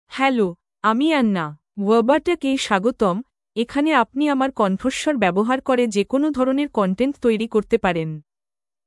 Anna — Female Bengali (India) AI Voice | TTS, Voice Cloning & Video | Verbatik AI
FemaleBengali (India)
Voice sample
Female
Anna delivers clear pronunciation with authentic India Bengali intonation, making your content sound professionally produced.